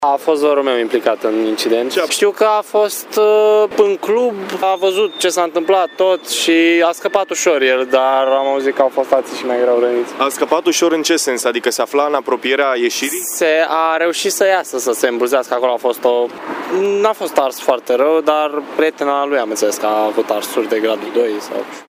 Un alt tânăr a venit la același spital să se intereseze de starea de sănătate a vărului său, care a reușit din fericire să se salveze și s-a ales doar cu câteva arsuri minore.